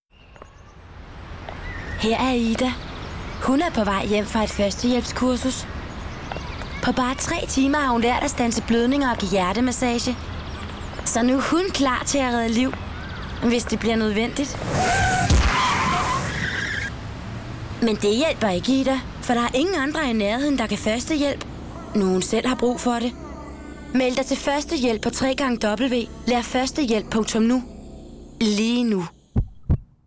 Sprecherin dänisch.
Sprechprobe: Industrie (Muttersprache):
female voice over danish. Educated actress from Denmark